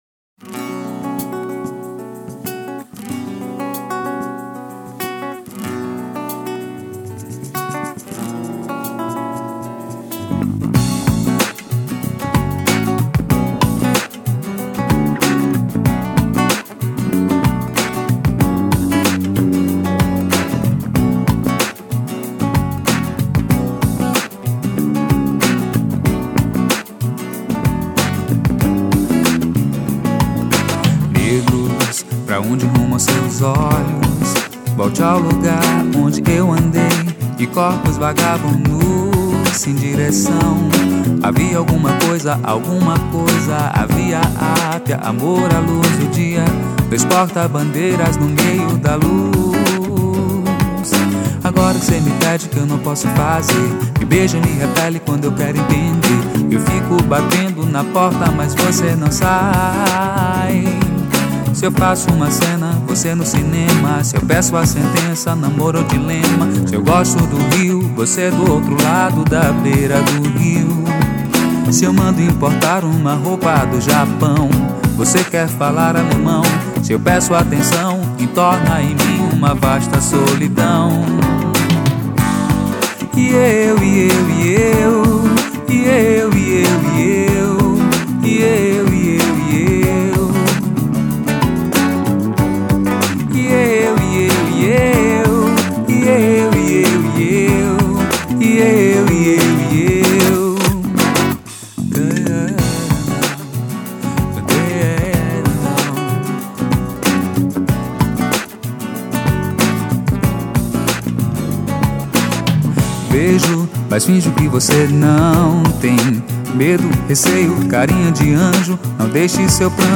824   03:57:00   Faixa:     Mpb
Clarinete, Saxofone Tenor
Violino I